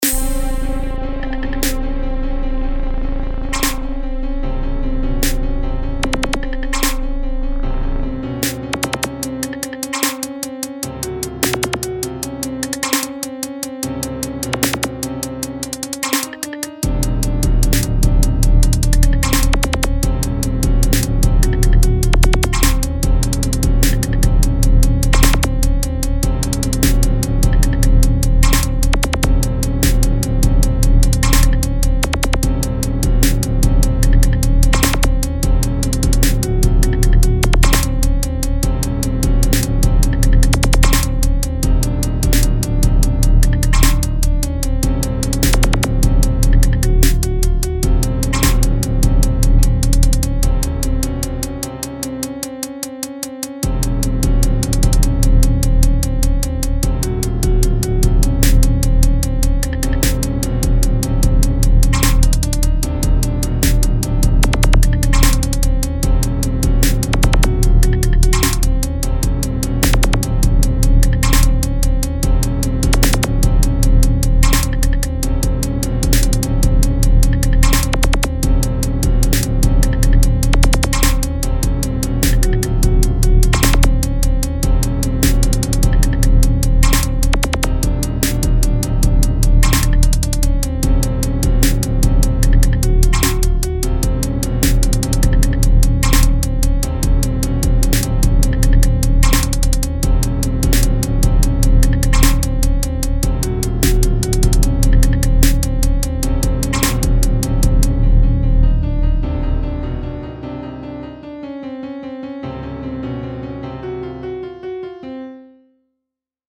Hip-hop Агрессивный 150 BPM